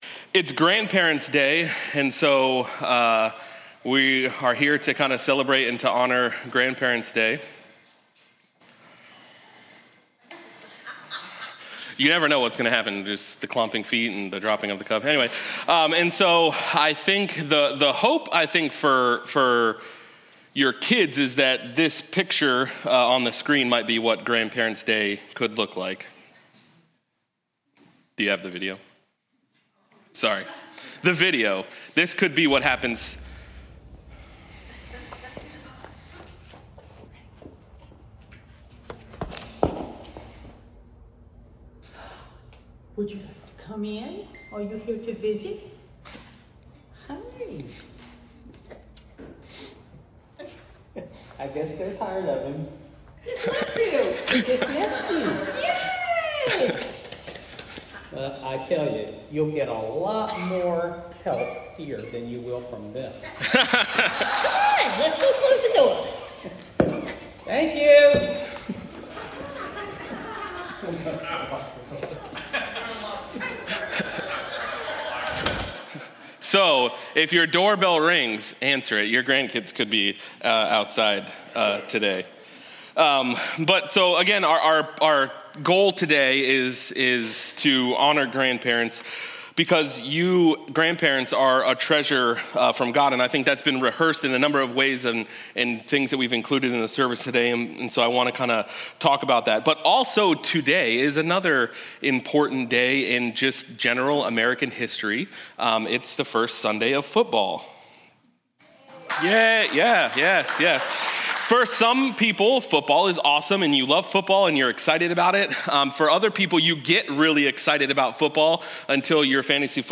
Sermon-9-07-25.mp3